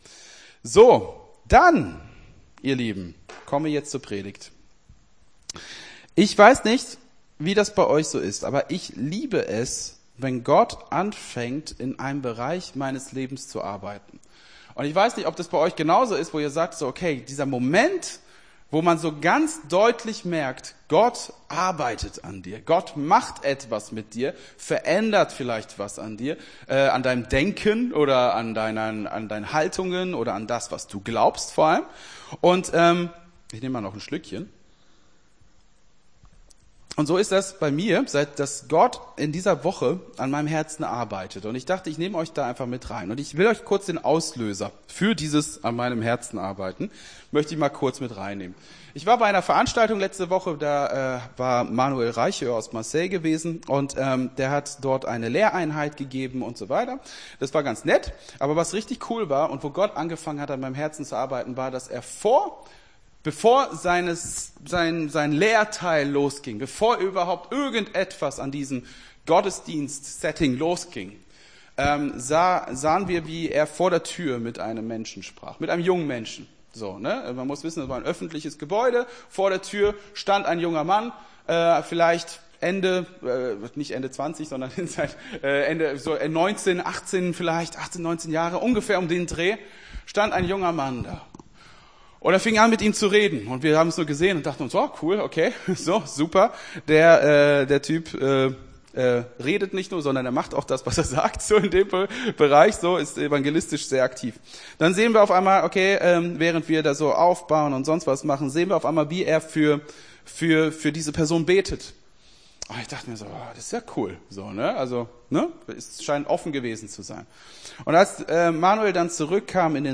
Gottesdienst 04.02.24 - FCG Hagen